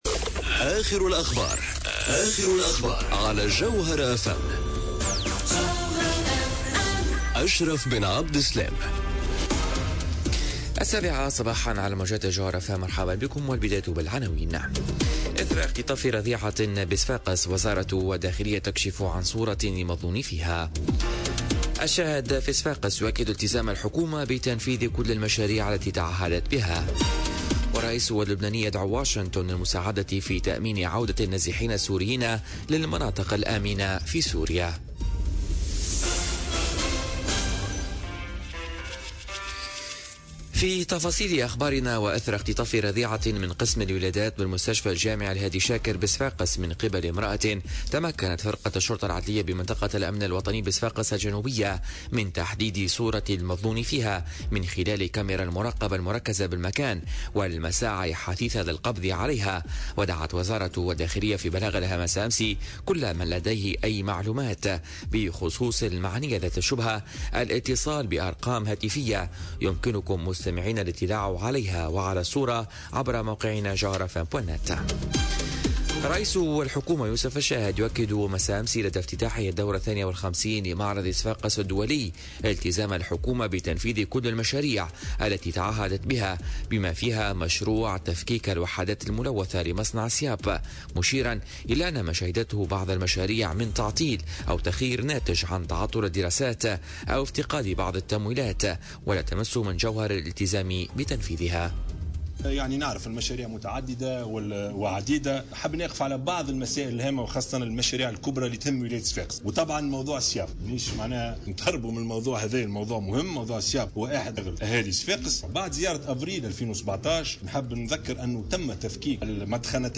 نشرة أخبار السابعة صباحا ليوم الإربعاء 27 جوان 2018